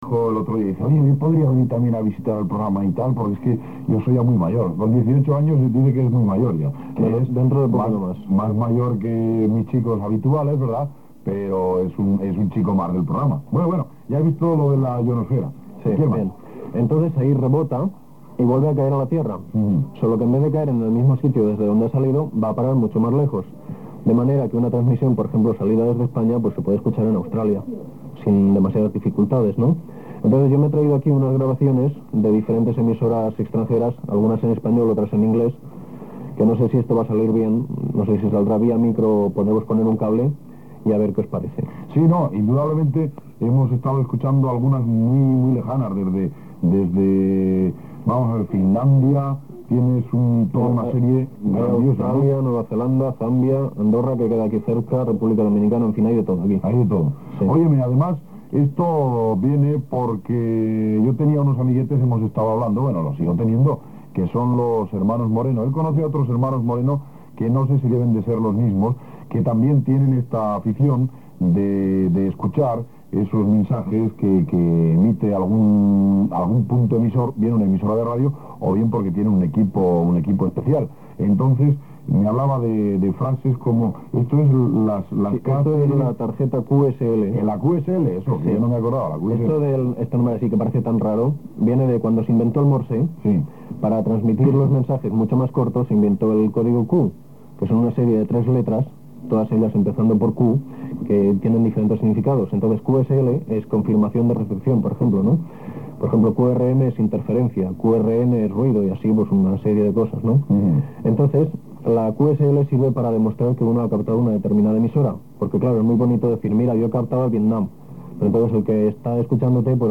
tema musical, trucada telefònica, tama musical
Gènere radiofònic Infantil-juvenil